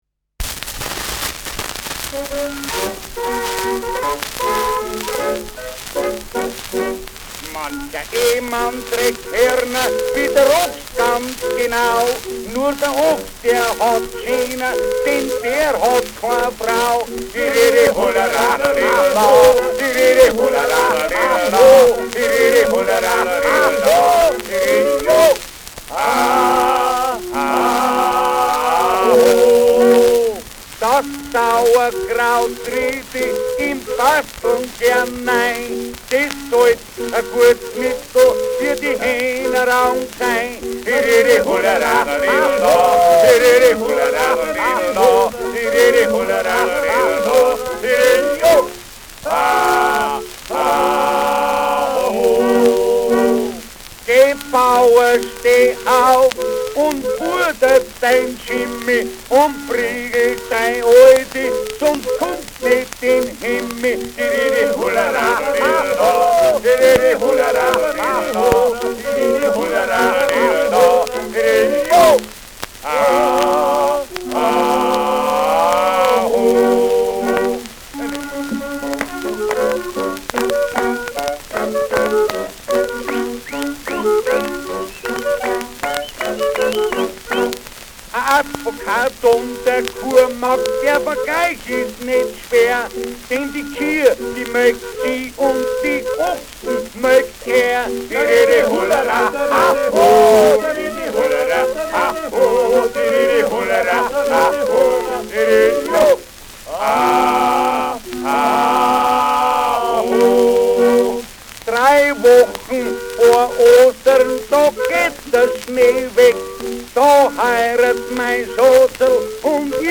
Schellackplatte
leichtes Rauschen : präsentes Knistern : zu Beginn Nadelgeräusch : um 1’05’’ vereinzeltes Knacken
Adams Bauern-Trio, Nürnberg (Interpretation)